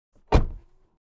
car_door_close_002.wav